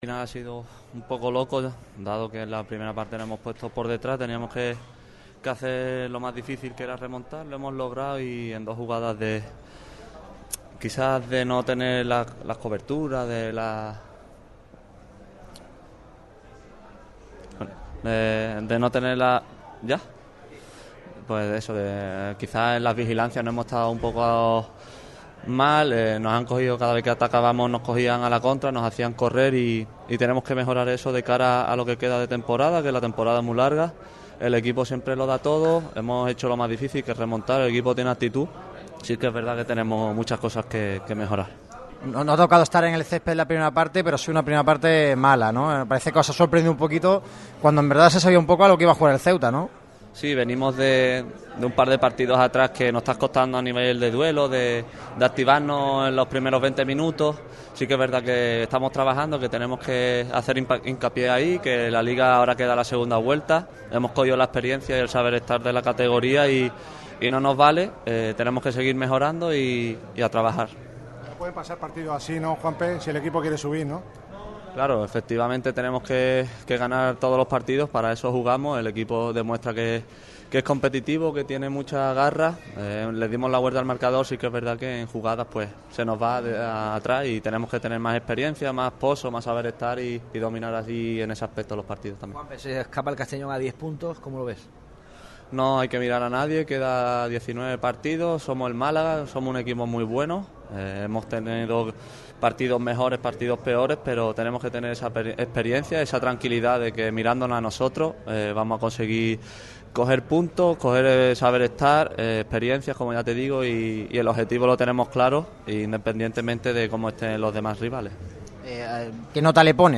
El centrocampista jerezano ha comparecido ante los medios tras la derrota malaguista en Ceuta (3-2).